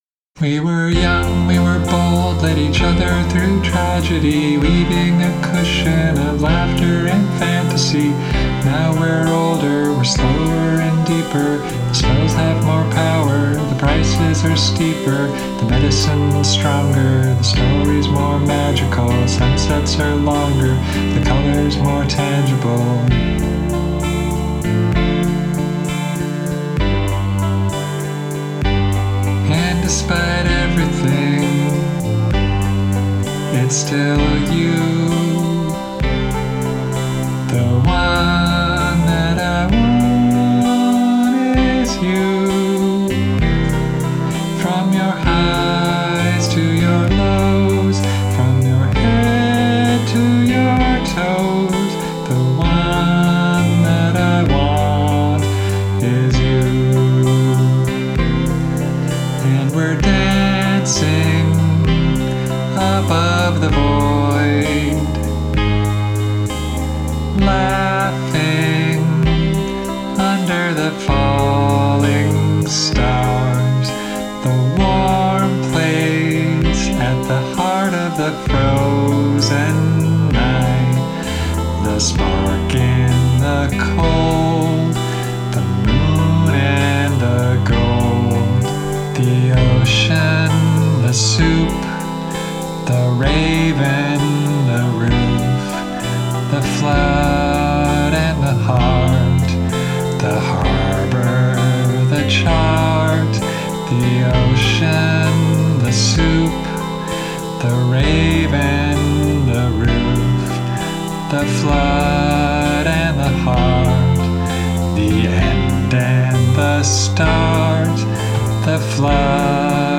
12/8 time